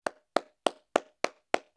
clap.wav